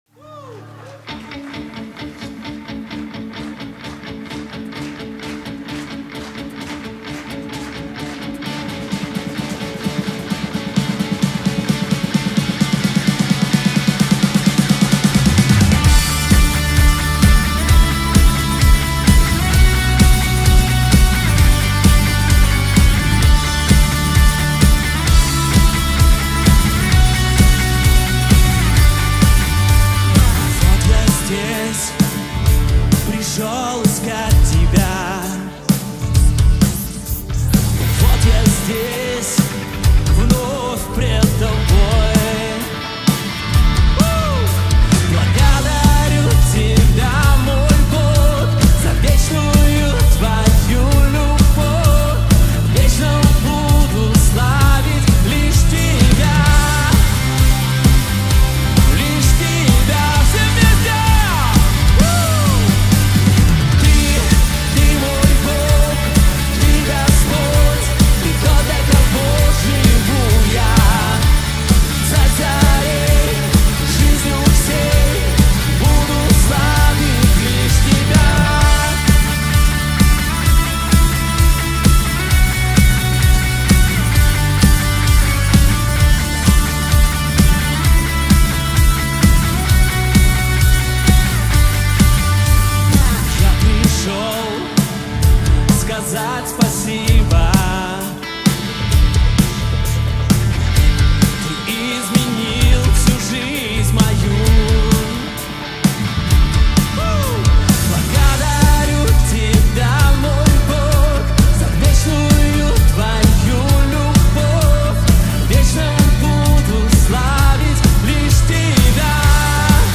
31 просмотр 111 прослушиваний 3 скачивания BPM: 125